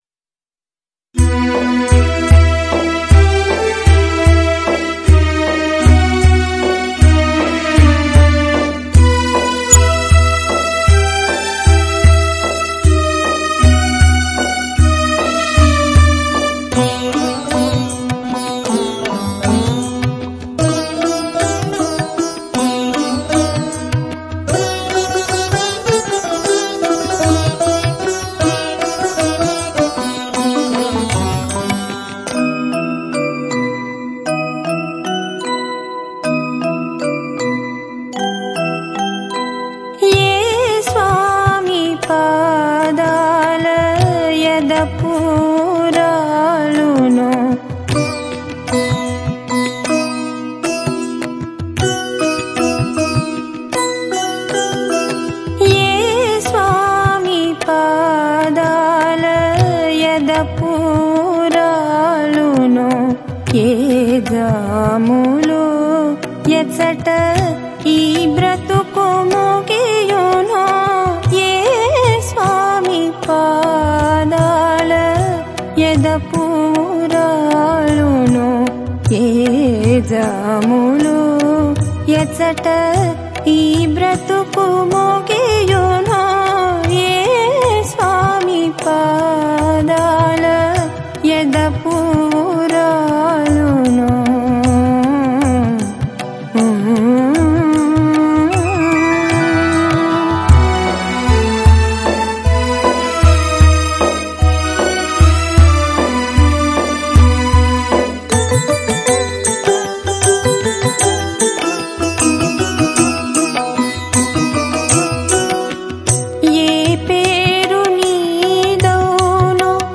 Ragam - Ragavardhani